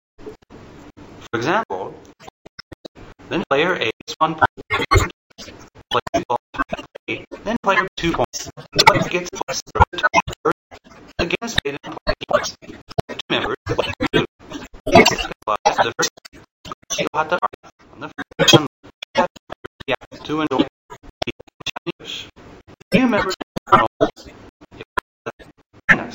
ＲＥＡＤＩＮＧ
(fast)